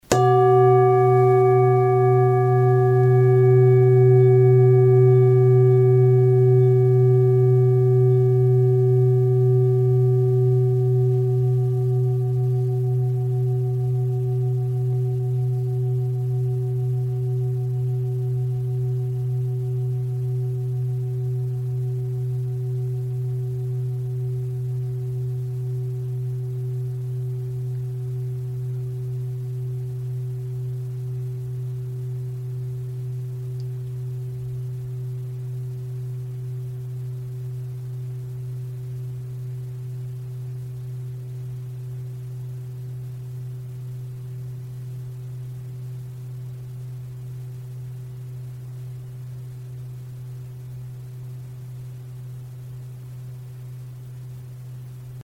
Struck or rubbed, the bowls produce a long-lasting, overtone-rich, and fine sound.
Sound sample Arhat singing bowl 2000g:
Arhat-Klangschale-2000g-Hoerprobe.mp3